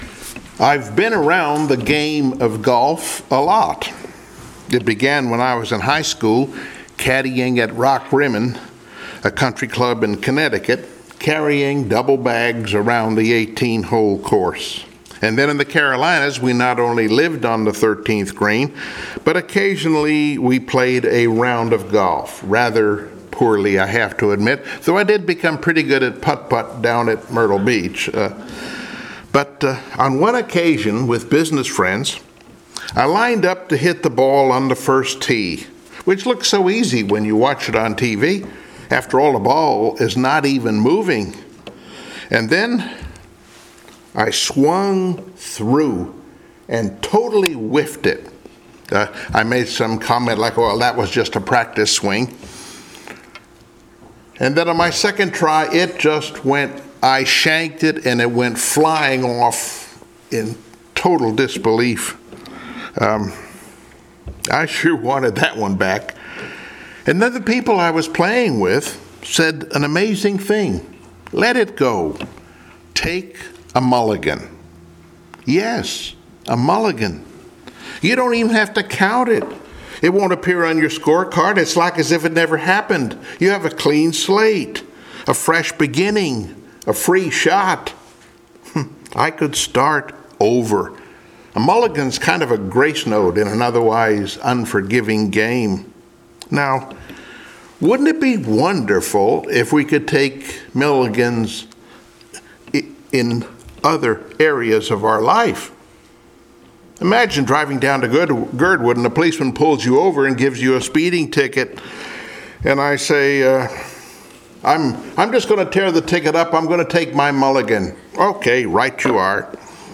Service Type: Sunday Morning Worship Download Files Notes Bulletin Topics: Forgiveness , Love , Redemption « “Love Pays Attention” “Being Chosen” »